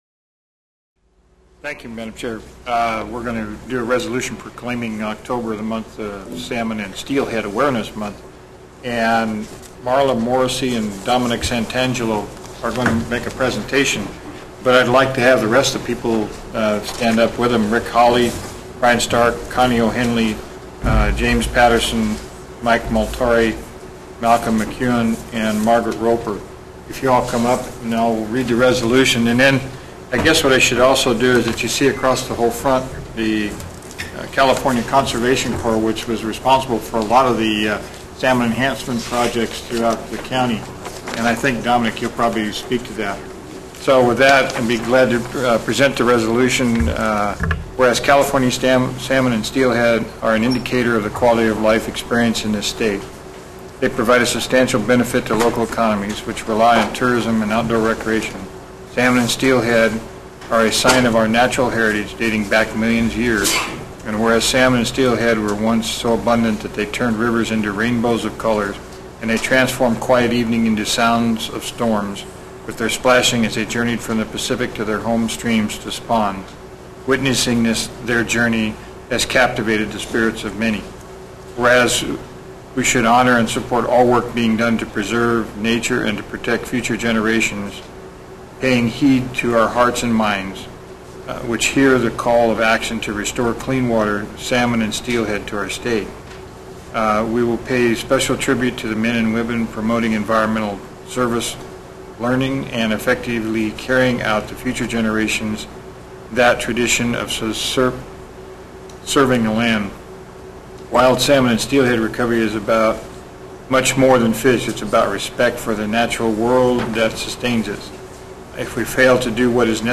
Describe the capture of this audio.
View video of San Luis ObispoBoard of Supervisors declaring October Salmon & Steelhead Awareness Month